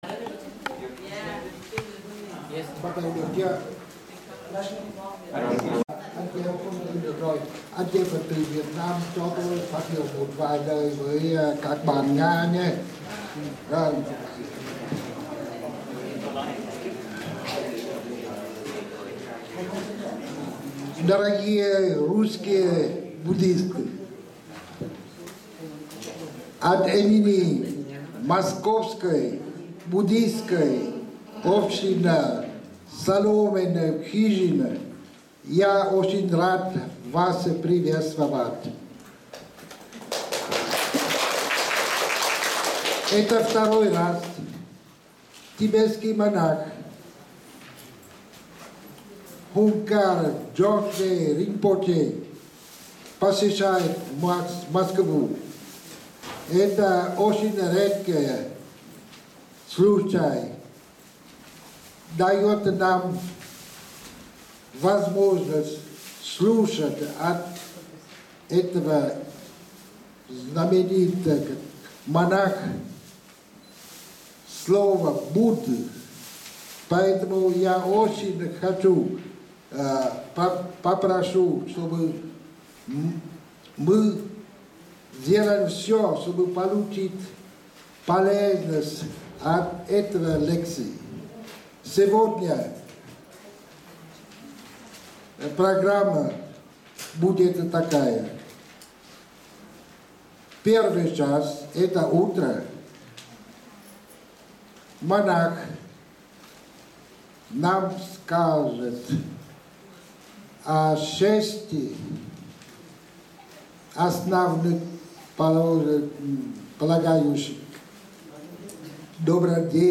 Dharma Teaching
5_Six_Paramitas_Moscow_Jun16_AM.mp3